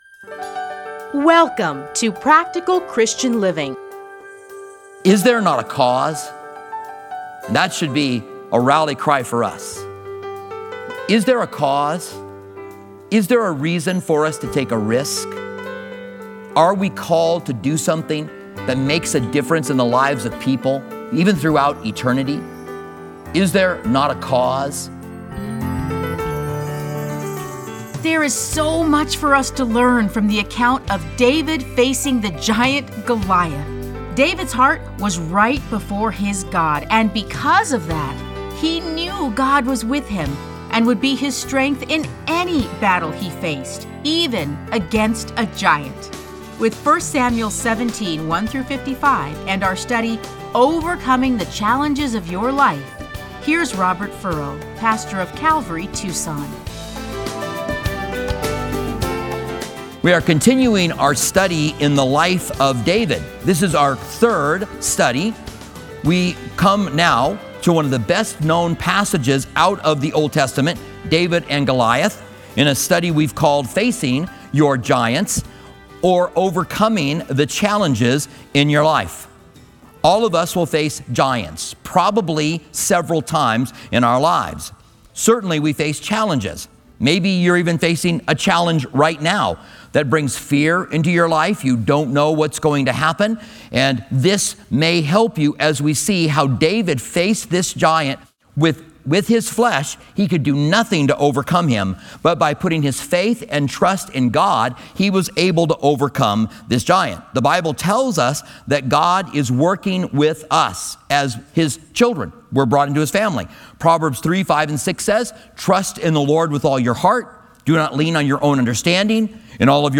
Listen to a teaching from 1 Samuel 17:1-55.